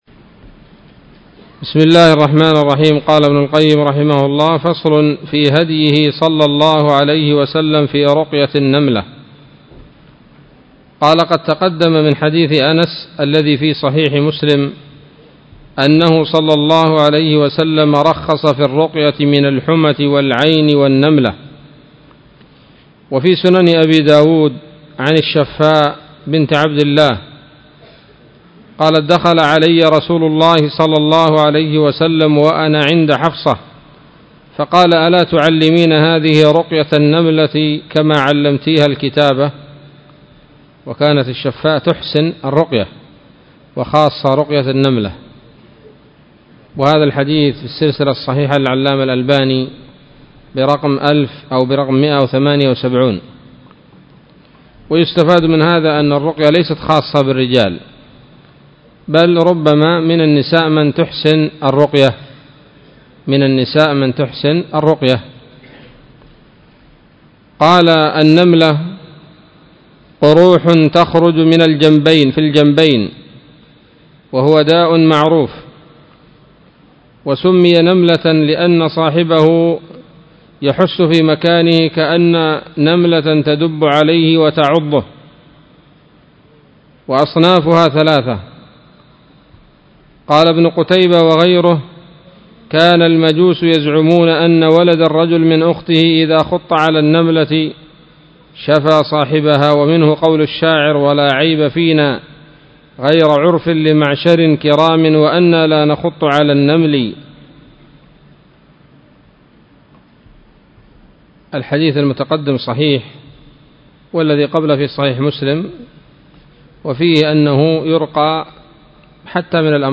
الدرس الثاني والخمسون من كتاب الطب النبوي لابن القيم